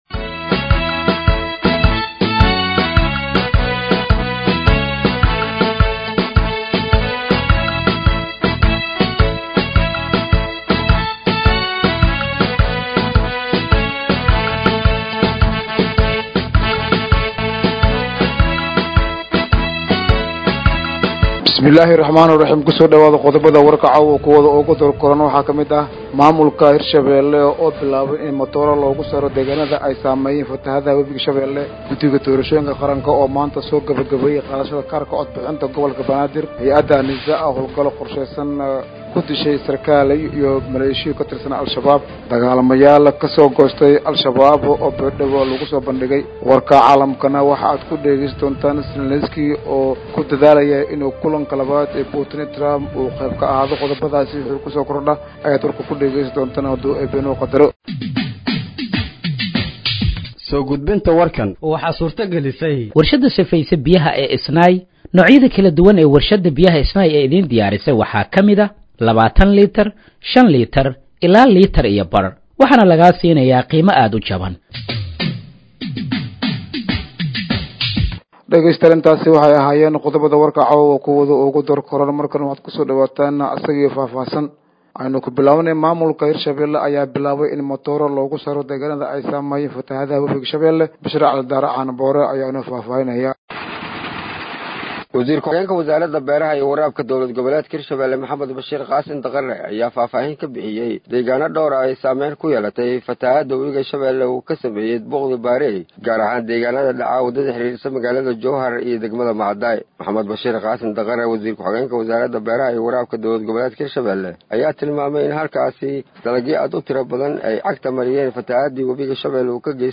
Dhageeyso Warka Habeenimo ee Radiojowhar 16/08/2025
Halkaan Hoose ka Dhageeyso Warka Habeenimo ee Radiojowhar